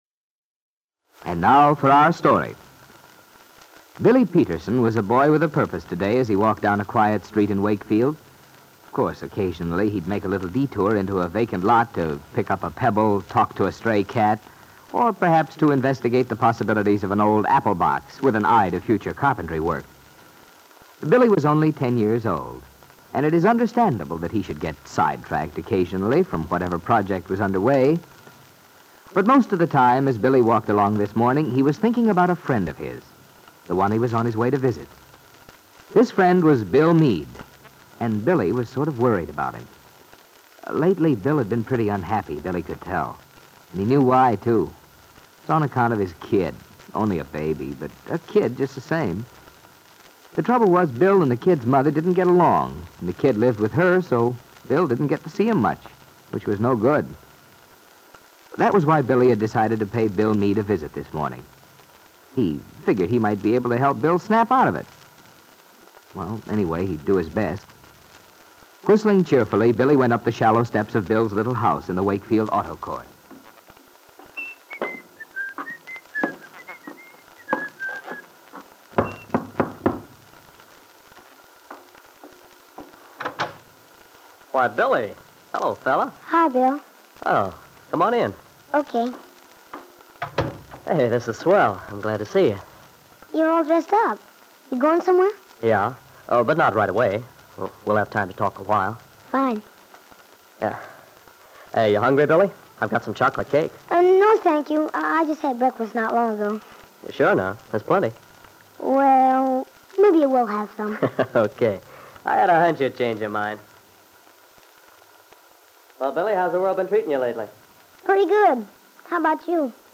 Aunt Mary was a soap opera that follows a story line which appears to have been broadcast in 1945 and early 1946. The plot features a love triangle and involves a young woman in a failing marriage who pays an extended visit to "friends" in Los Angeles to conceal a dark secret from her father and others back in Wakefield.